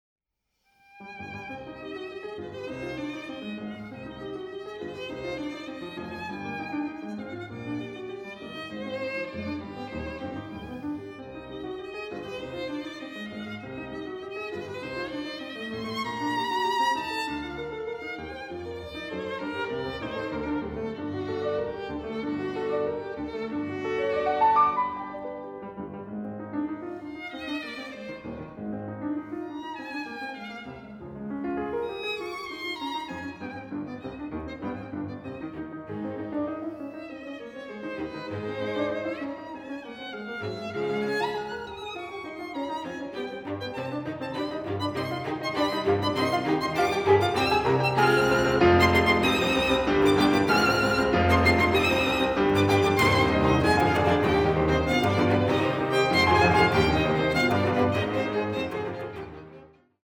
IV. Finale. Allegro comodo